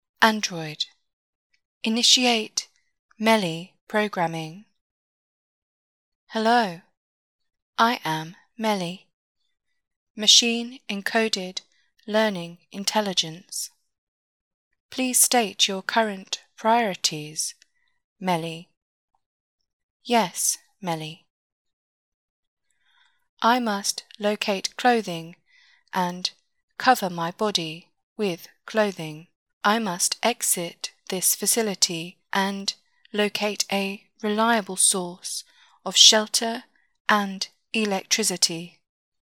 Robot Voice Audio Tutorial
This is a simple 8-band equalizer, and these settings boost the bass frequencies, while diminishing the frequencies around 7.3K.  Note also that the main volume has been amplified by 250%.
MELI-example-EQ.mp3